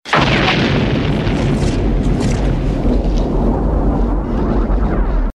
Explosion Free sound effects and audio clips
big_explosion_with_electricity_crackles_2cw.wav